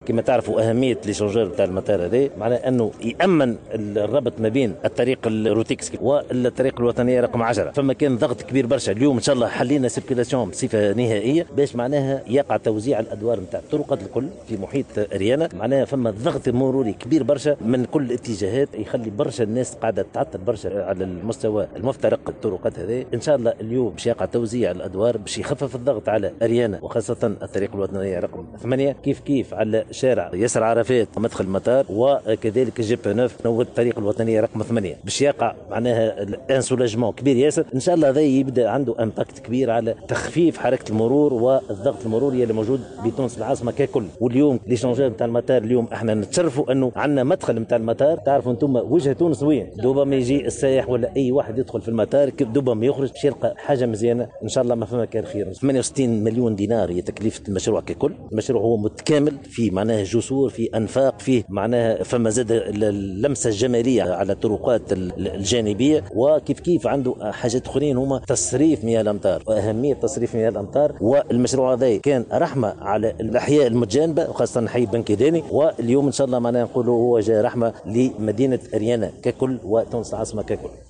وقال في تصريح لمراسلة "الجوهرة أف أم" إن هذا المشروع الذي تبلغ كلفته 68 مليون دينار، سيمكّن من تخفيف الضغط المروري بالنسبة لمتساكني أريانة وسكرة وكذلك تسهيل حركة المرور على الطريق الوطنية رقم 8 وعلى مستوى شارع ياسر عرفات.